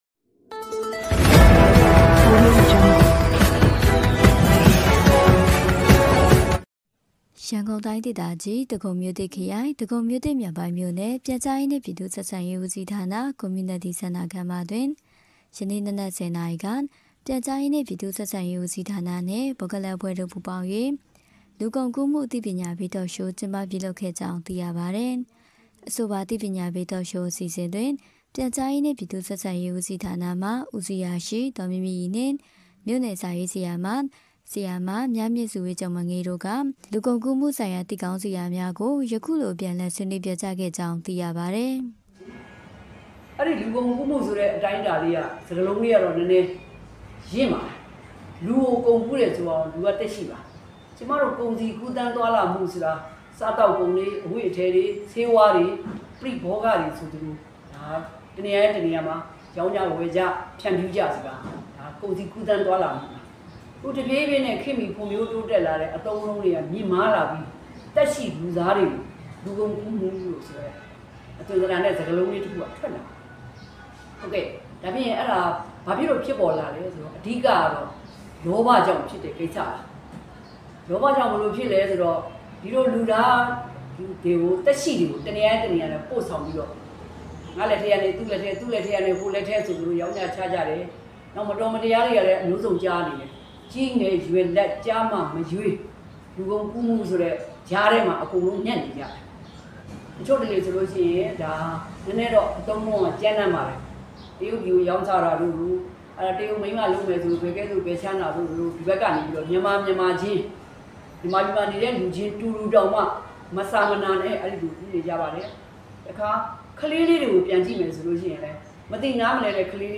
ဒဂုံမြို့သစ်(မြောက်ပိုင်း)မြို့နယ်တွင် လူကုန်ကူးမှု အသိပညာပေး Talk Show...